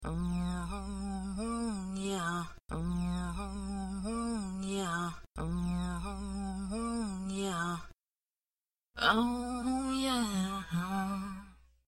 描述：Rev "Oh Yea" Vocal w/ fat mode and echo effect enabled.
标签： 90 bpm Chill Out Loops Vocal Loops 1.07 MB wav Key : Unknown
声道立体声